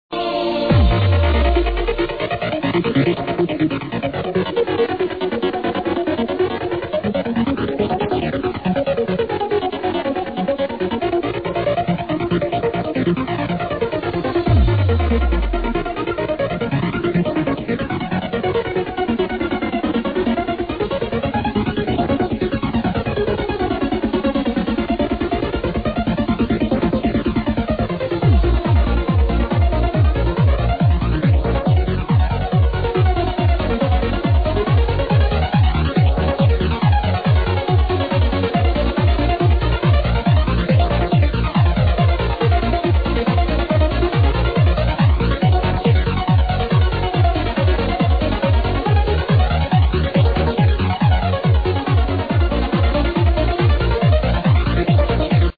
easy old trancer